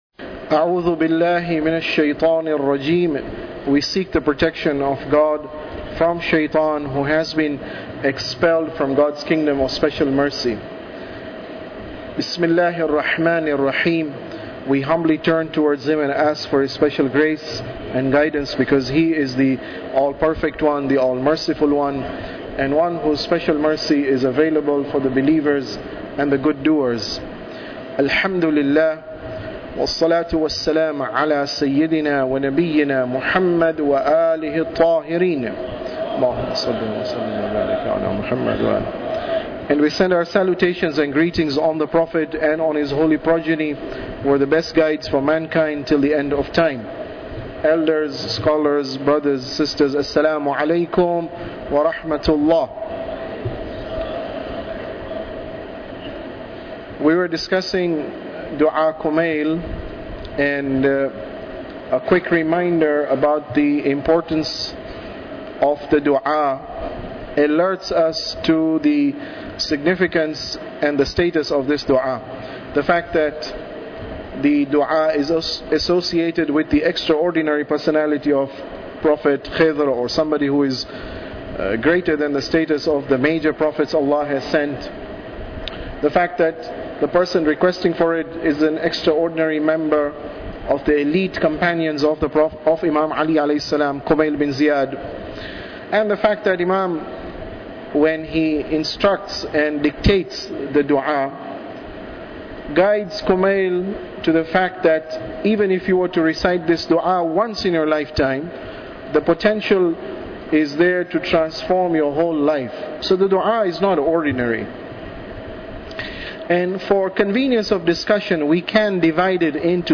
Tafsir Dua Kumail Lecture 23